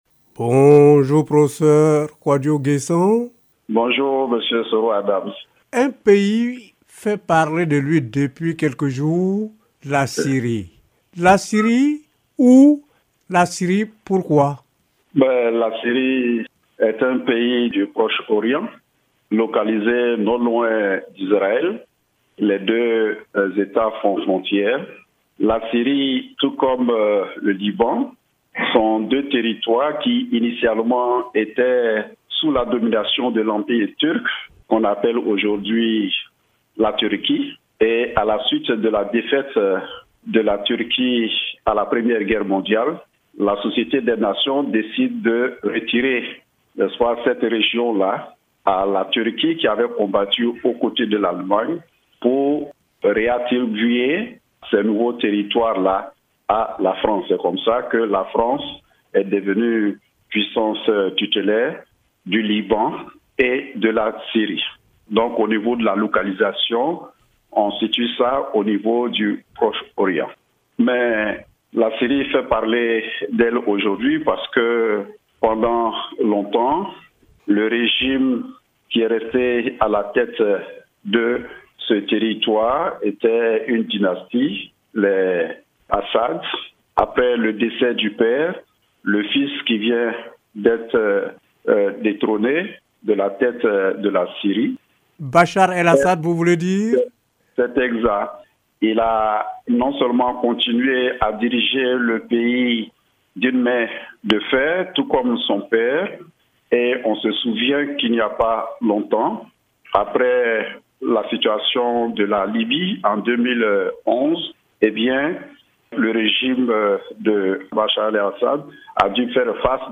suivons l’entretien…